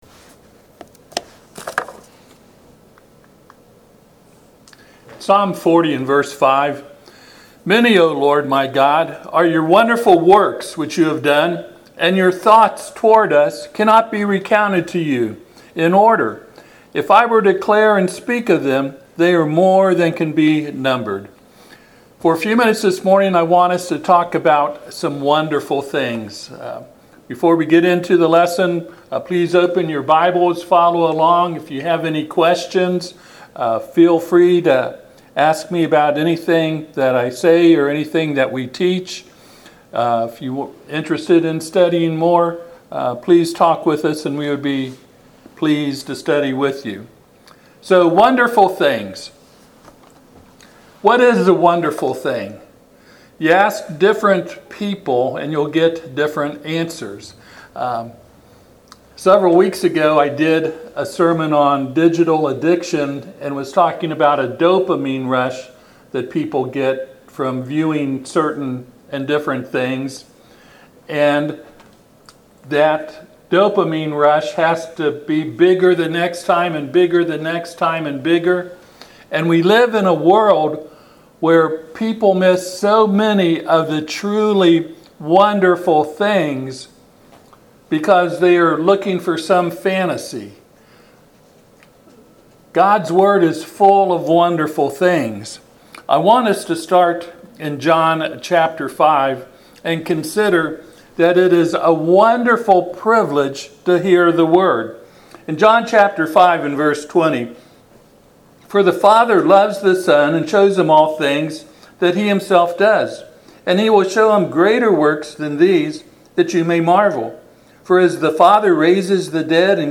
Passage: Psalm 40:5 Service Type: Sunday AM